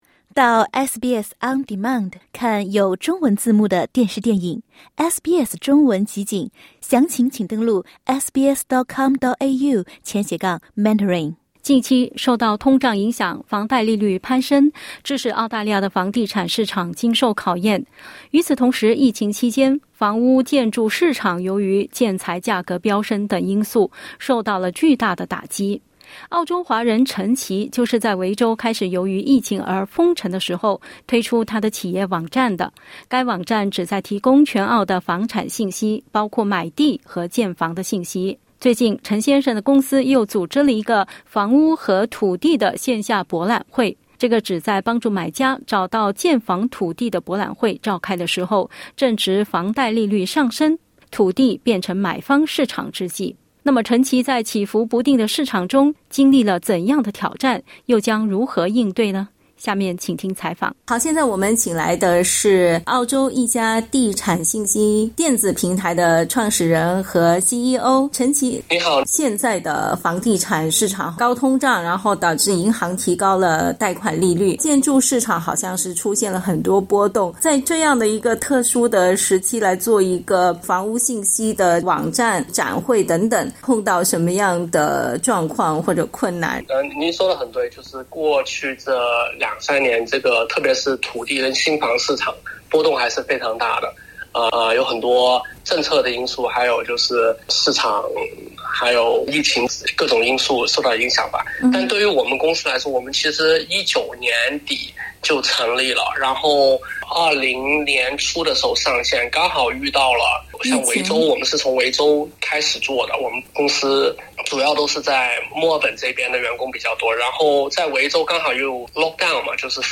创新企业如何面对疫情和通胀挑战？【企业家访谈】